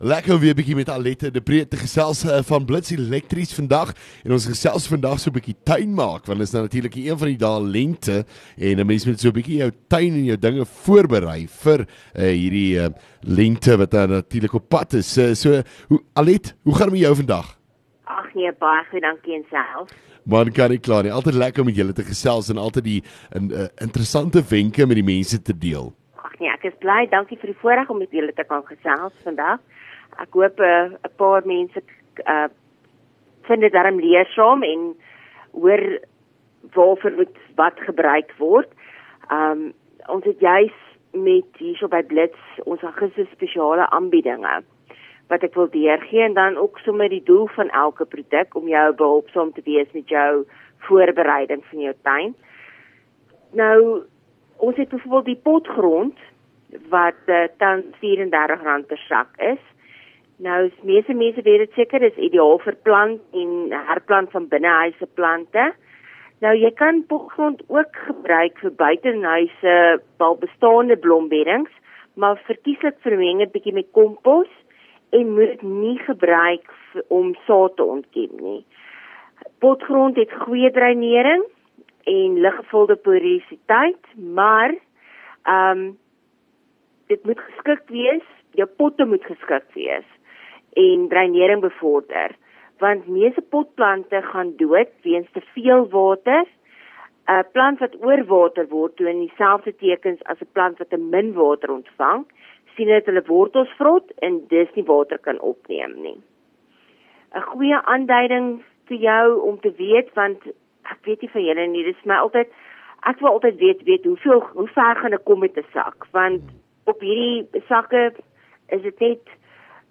LEKKER FM | Onderhoude 14 Aug Blits Elektrisiëns